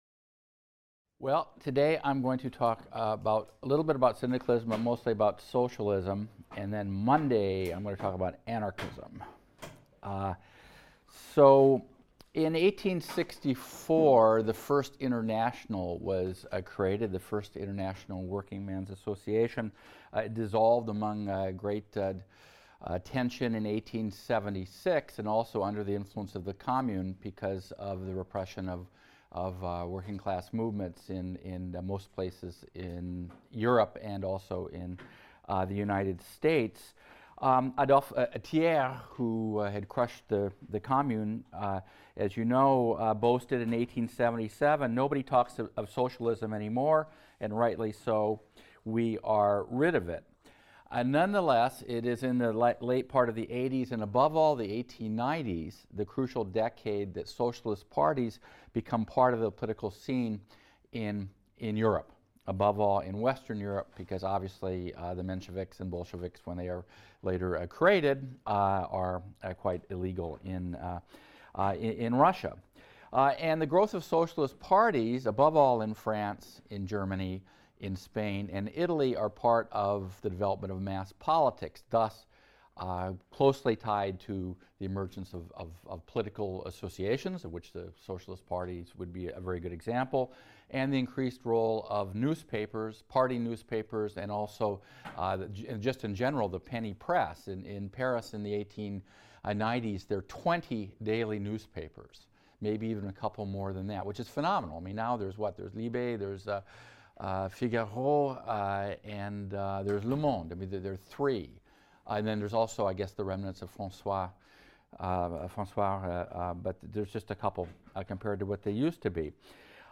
HIST 276 - Lecture 7 - Mass Politics and the Political Challenge from the Left | Open Yale Courses